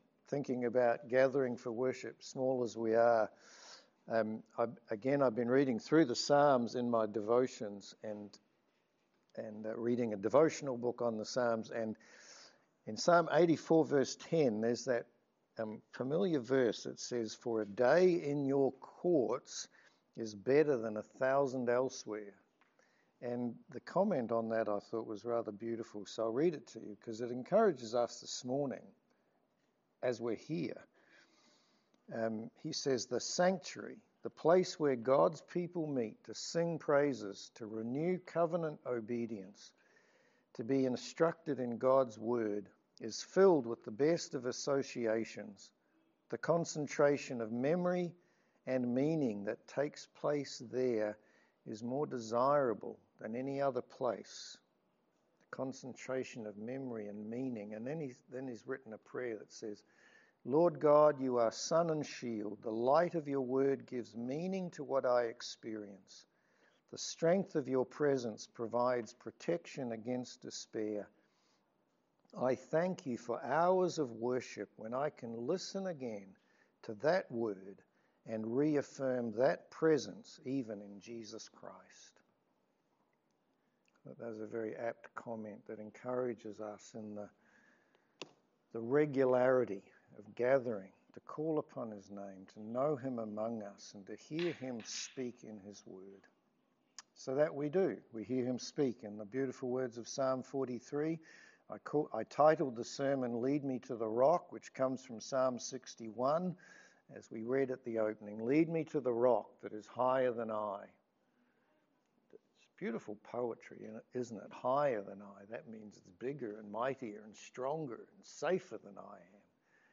Psalm 46 Service Type: Sermon There are many things in life that can leave us feeling fearful.